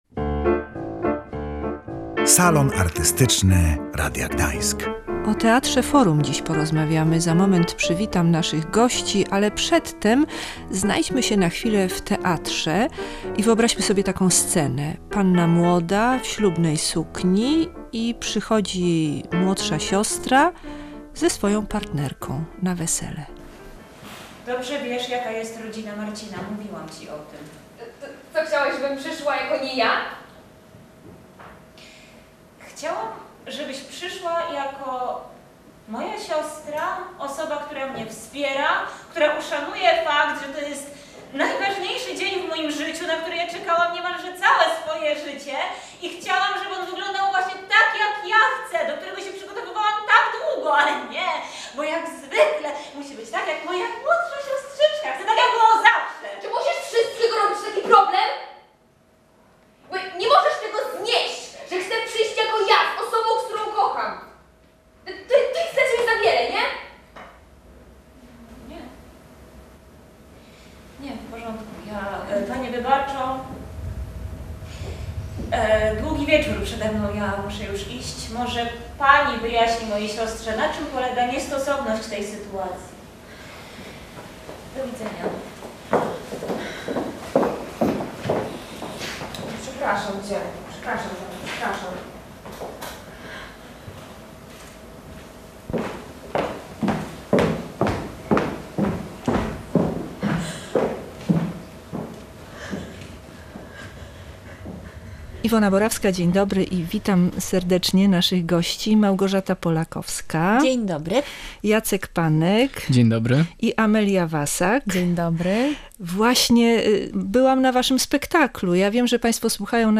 W studiu